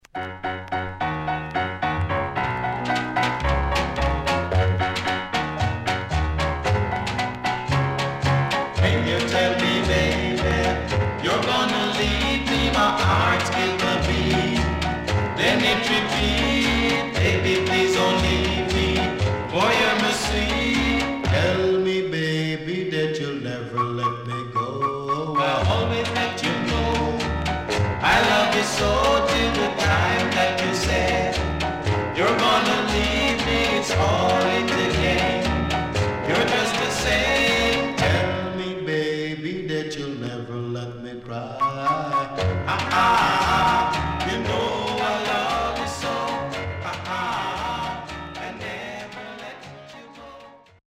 Early 60's
SIDE A:うすいこまかい傷ありますがノイズあまり目立ちません。